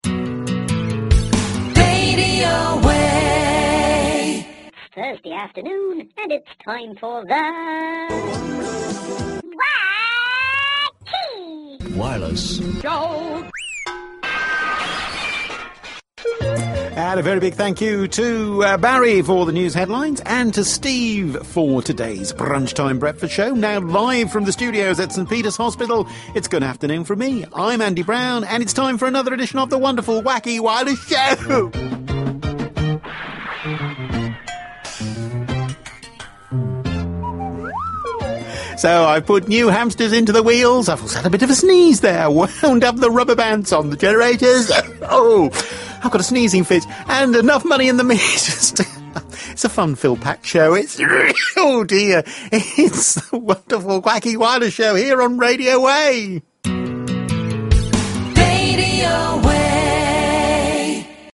a sneezing fit at the start of the show